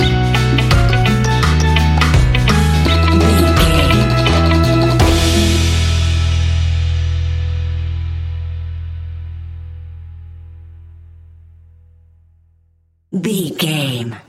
Ionian/Major
Slow
steelpan
drums
brass
guitar